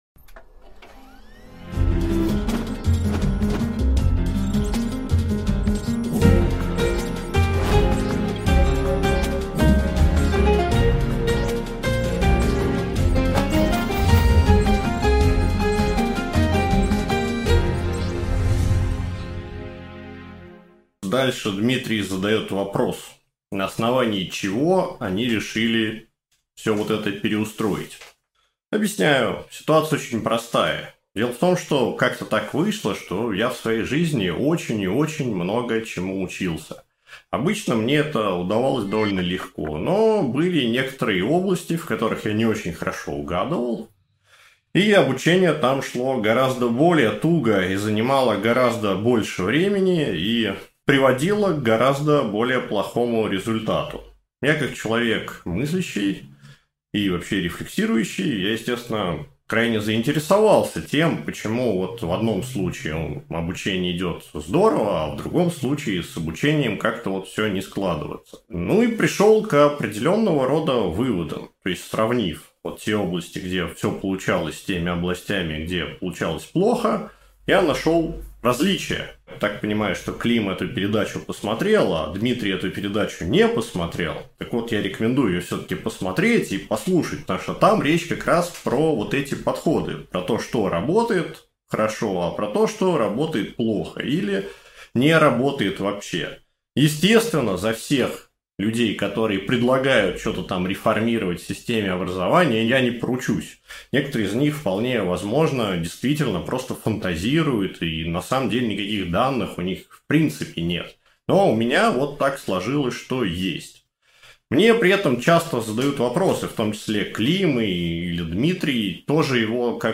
Аудиокнига Образование. Эффективность в работе, в хобби и в получении знаний | Библиотека аудиокниг